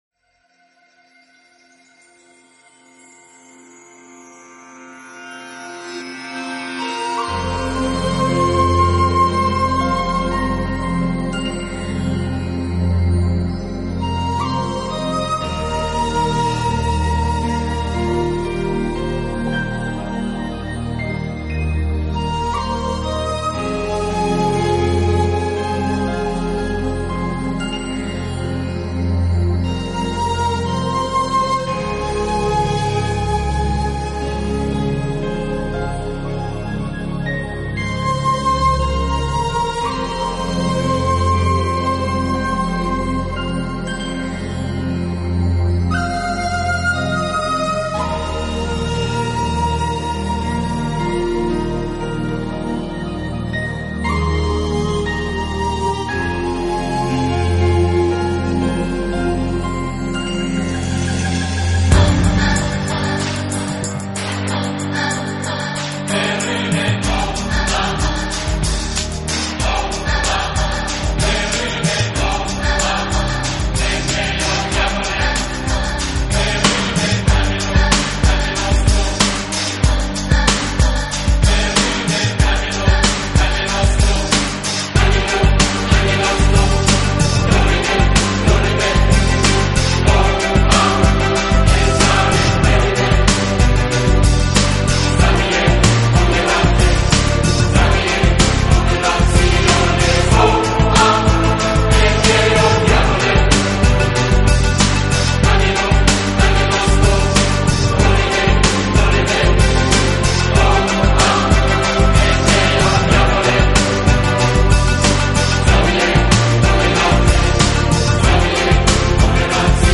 【新世纪音乐】
是领唱上，都加重了女声的成分，因而整体风格都显得更柔和、更温暖。